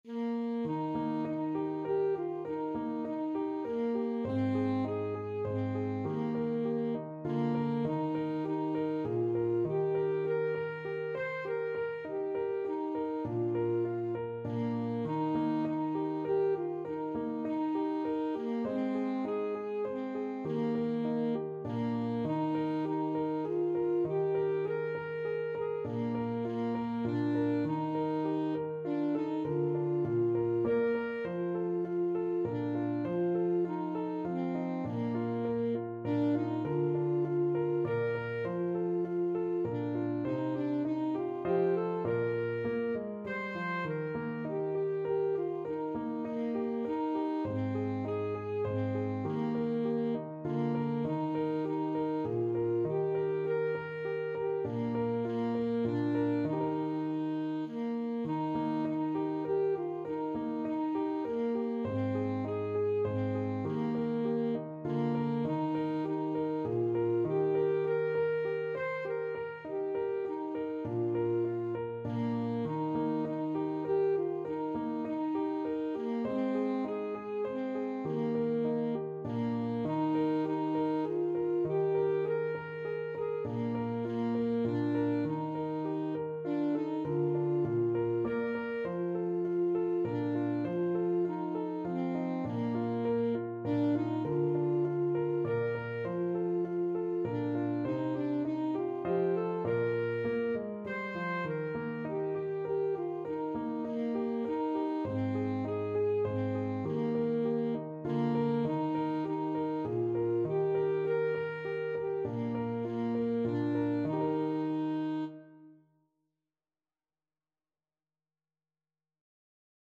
Free Sheet music for Alto Saxophone
Bb4-C6
Moderato
3/4 (View more 3/4 Music)
Saxophone  (View more Easy Saxophone Music)
Classical (View more Classical Saxophone Music)